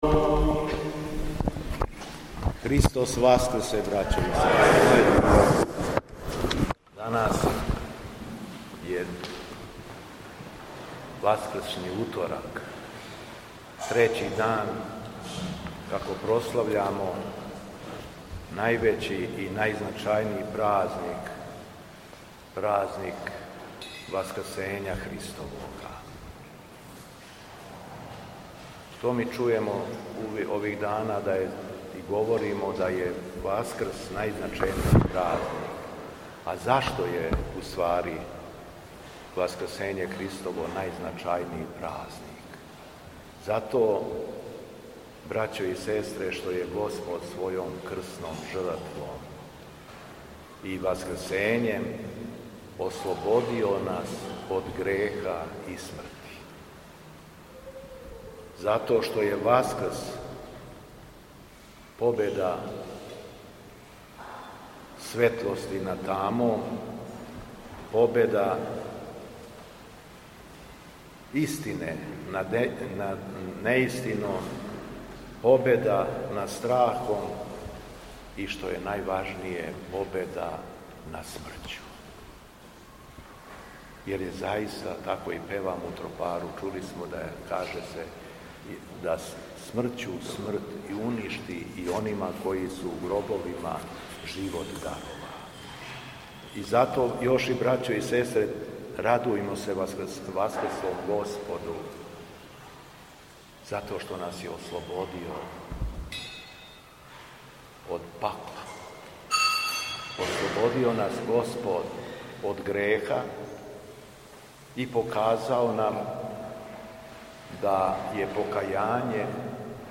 ВАСКРСНИ УТОРАК У ХРАМУ СВЕТОГА САВЕ У КРАГУЈЕВАЧКОМ НАСЕЉУ АЕРОДРОМ
Беседа Његовог Високопреосвештенства Митрополита шумадијског г. Јована